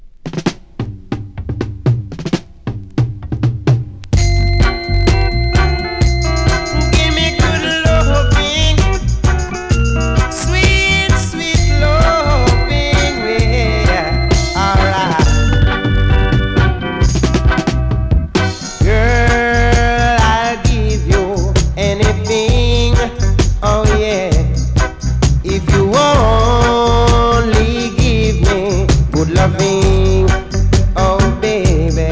12"/Vintage-Dancehall